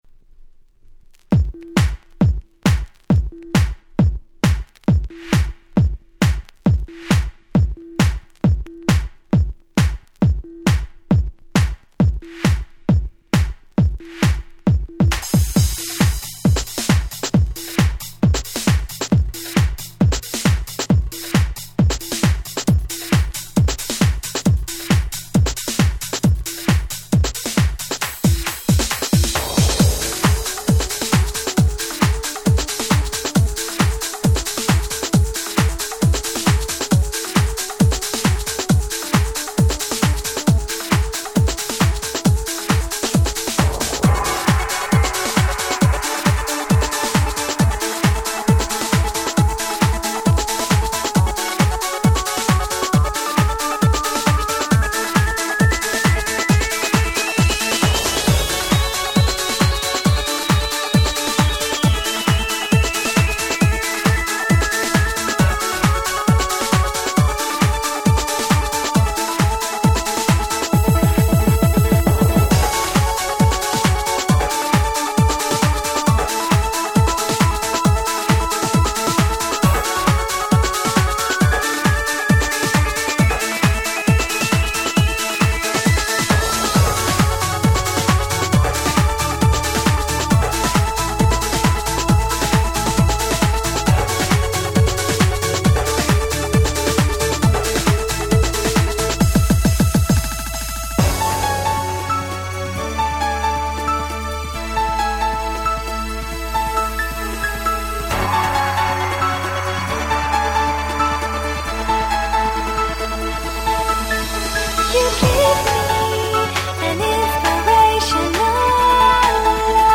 96' Super Hit House/Trance !!